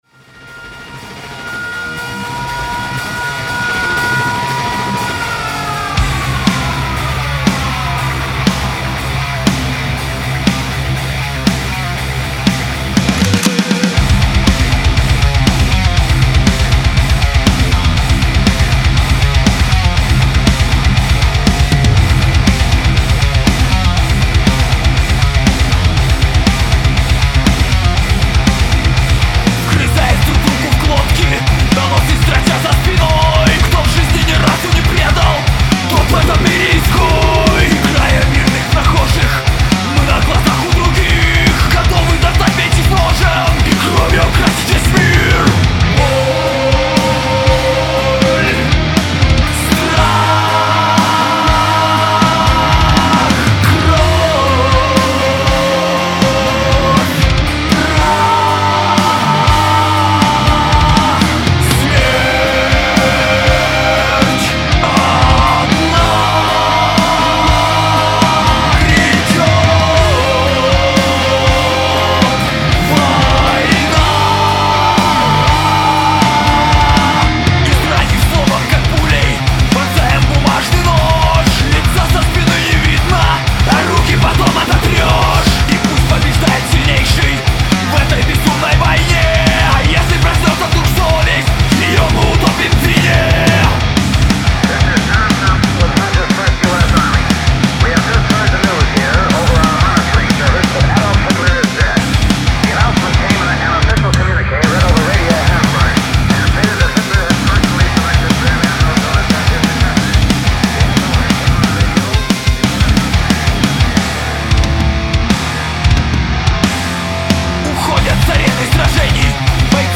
Метал на зацен (ворошим старое))
Всегда было интересно как старые записи записанные и сведенные по неопытности на старых слабеньких плагинчиках могут зозвучать на новых.
Neural DSP Fortin Nameless
Утопил вокал, сделал ближе гитары.